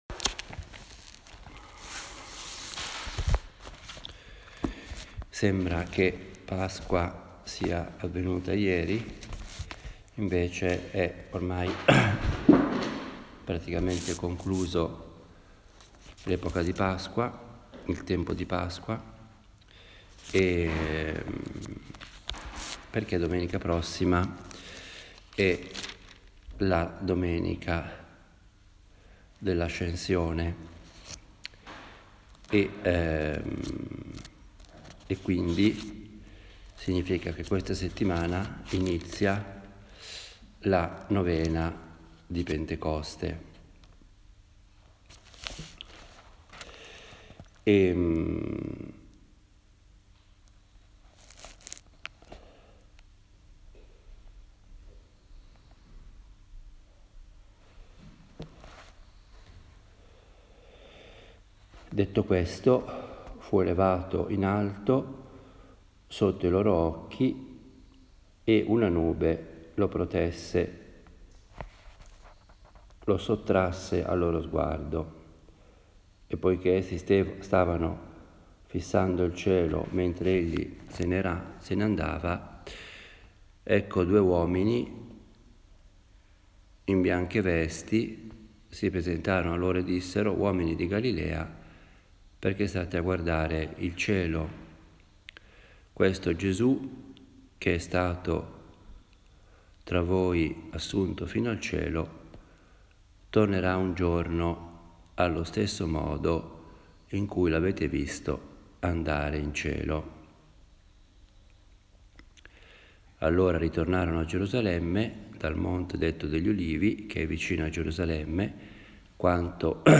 Una “meditazione” è un genere omiletico diverso dalla predica, dal discorso, o dall’allocuzione. Ha il carattere piano, proprio di una conversazione familiare e io la intendo come il mio dialogo personale – fatto ad alta voce – con Dio, la Madonna, ecc. In genere do un titolo alle meditazione e cerco di fare molto riferimento alla scrittura, in particolare al vangelo. Le meditazioni che si trovano sul blog sono semplici registrazioni – senza nessuna pretesa particolare – di quelle che faccio abitualmente.